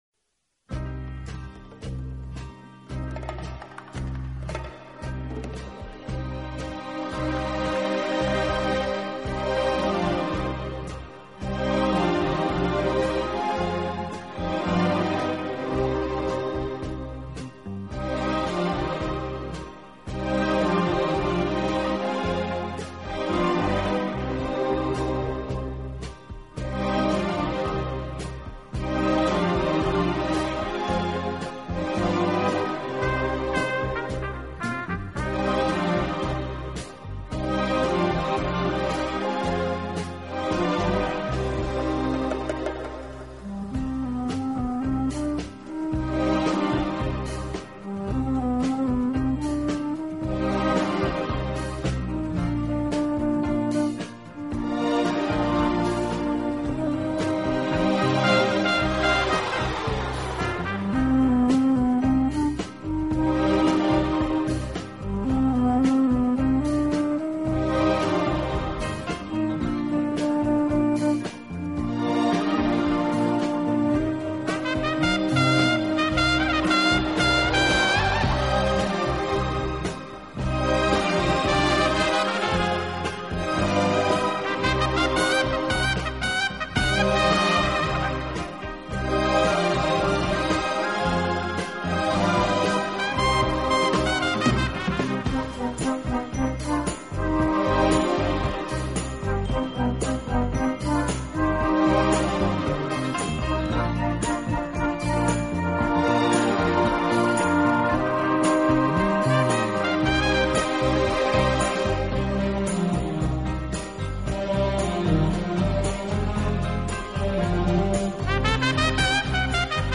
轻快、节奏鲜明突出，曲目以西方流行音乐为主。